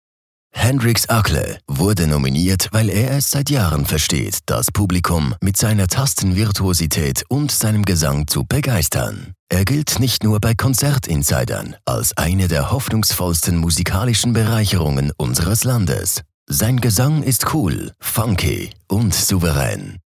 OFF-Kommentar Hochdeutsch (CH)
Sprecher mit breitem Einsatzspektrum.